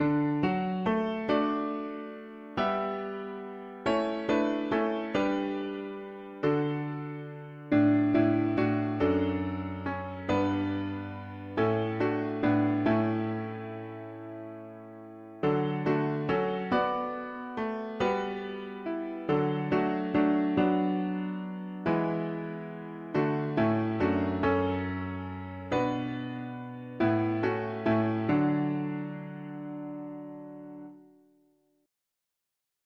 Key: D major
Alternate words Morning has broken Tags english christian winter 4part chords